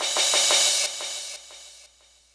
crash02.wav